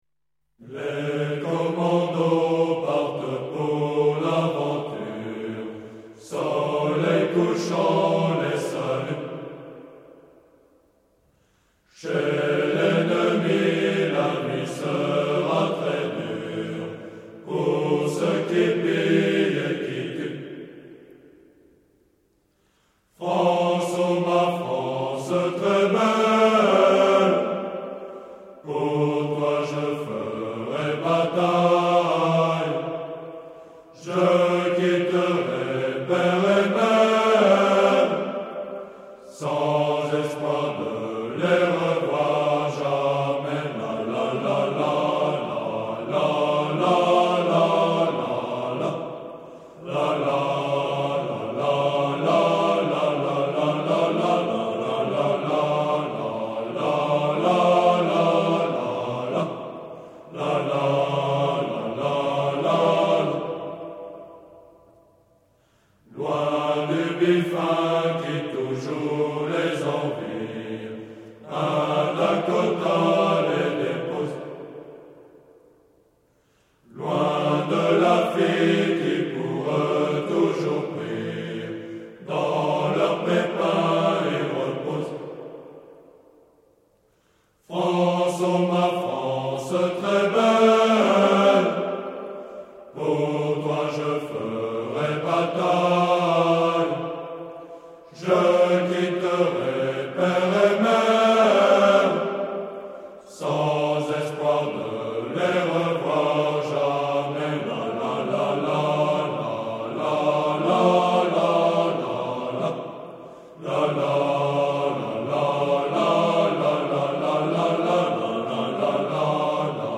Chants scouts